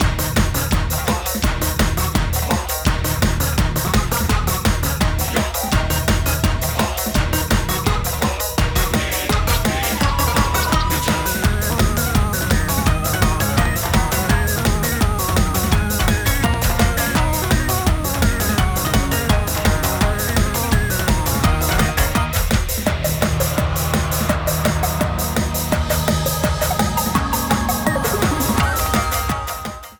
Frontrunning music